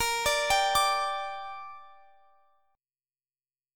Bb6 Chord
Listen to Bb6 strummed